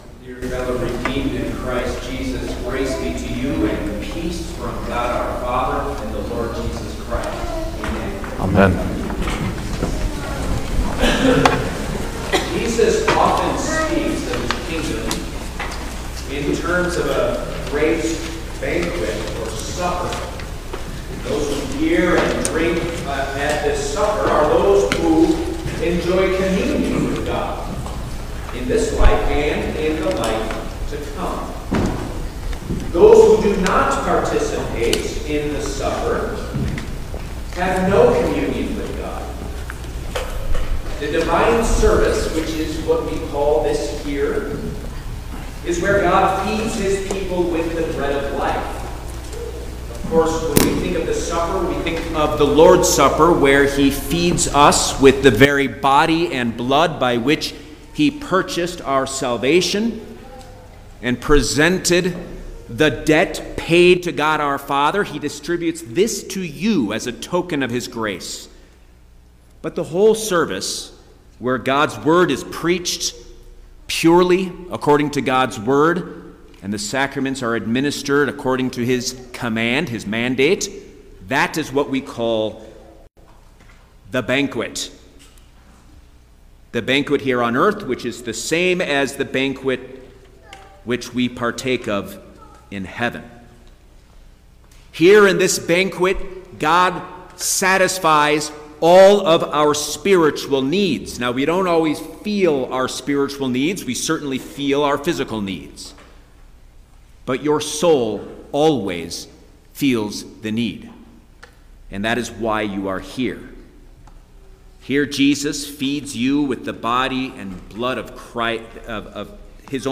June-9_2024_Second-Sunday-after-Trinity_Sermon-Stereo.mp3